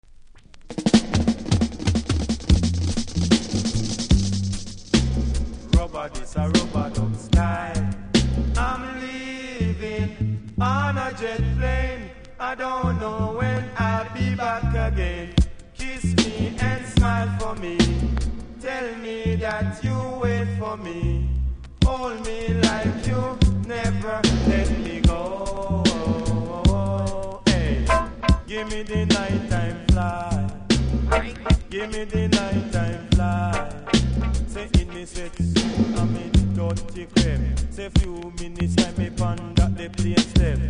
REGGAE 80'S
キズもノイズも少なく良好ですので試聴で確認下さい。